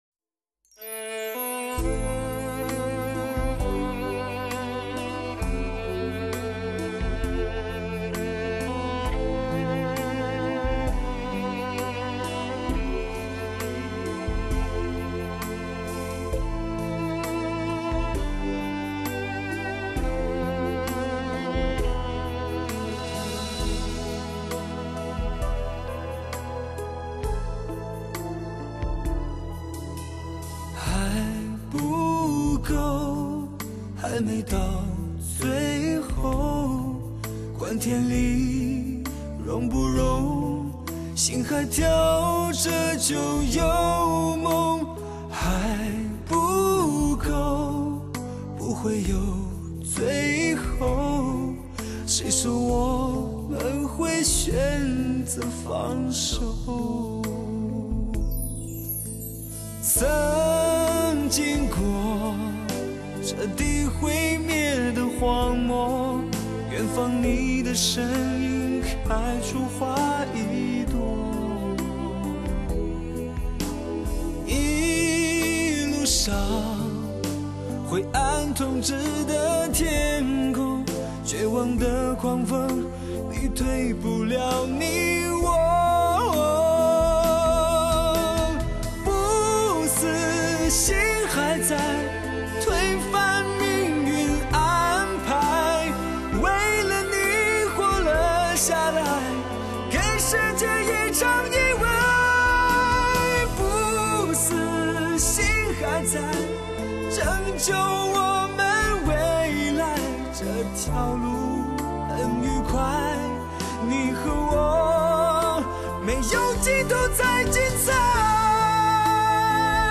Magix Virtual Live高临场感CD。